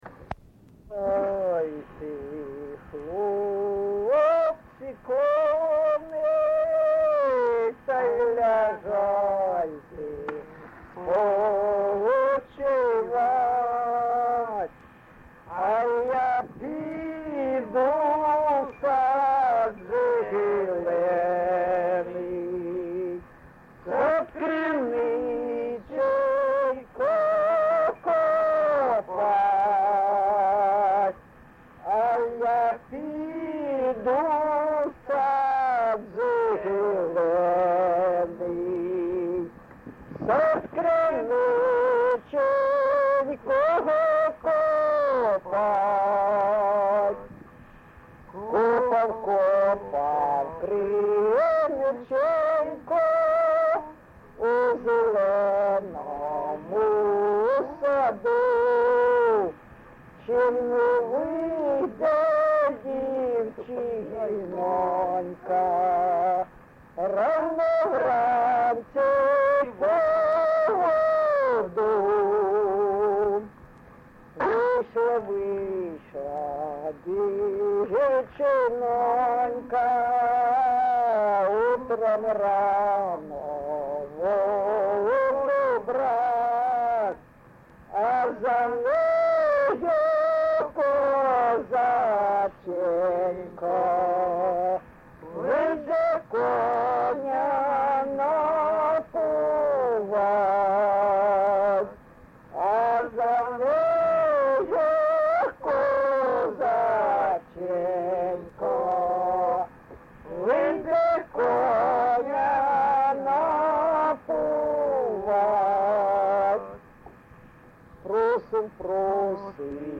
ЖанрПісні з особистого та родинного життя, Козацькі